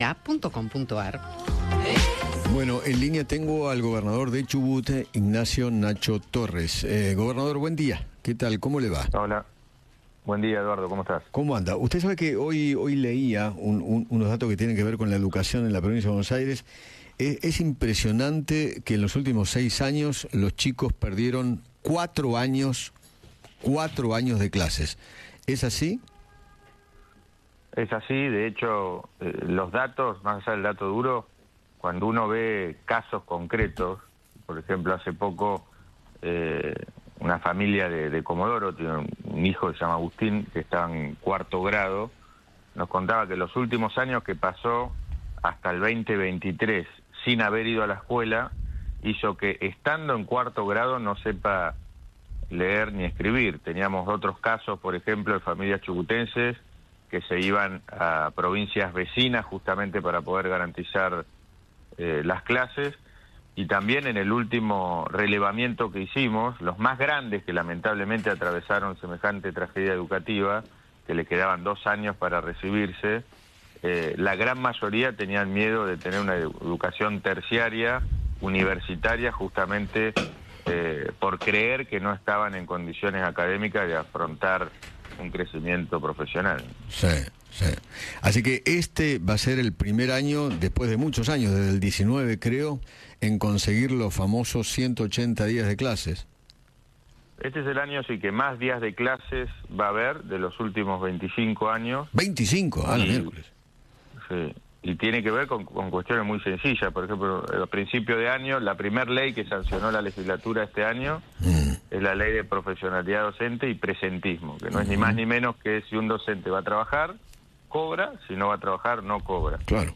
El gobernador de Chubut Ignacio Torres conversó con Eduardo Feinmann sobre el debate por el Presupuesto 2025 y se refirió a los avances en educación en su provincia.